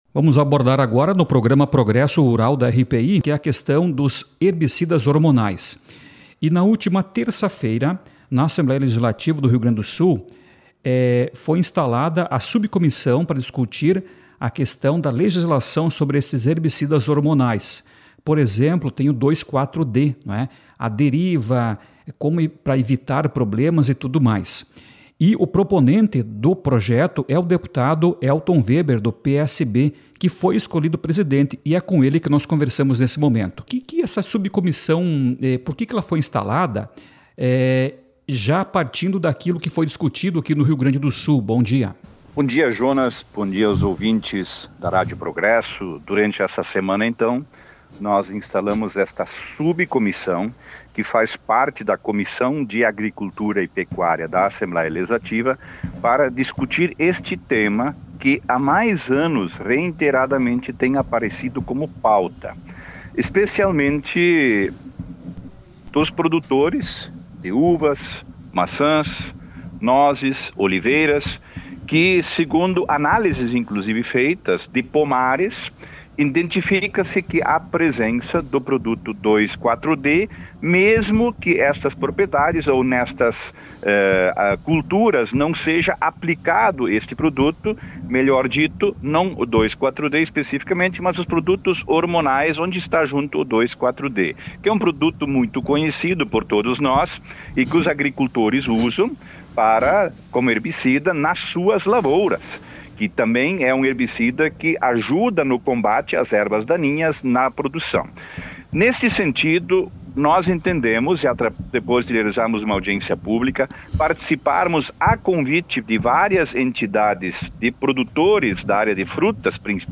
ENTREVISTA-PROGRESOS-RURAL-20.04-ELTON.mp3